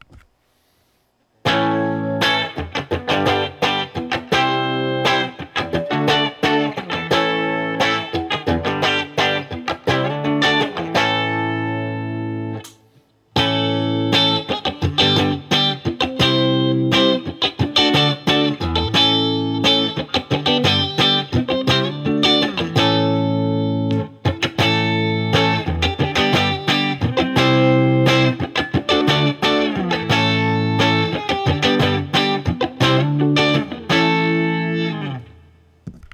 All recordings in this section were recorded with an Olympus LS-10.
Next, I take both guitars and through the Tiny Tweed setting (basically a Fender Champ type of sound), I run through a simple barre chord progression on each guitar using each of the possible pickup positions. Both guitars have all knobs on 10 for these recordings.
2015 S-100 All Pickups
Guild-NS-S100-AllPositions.wav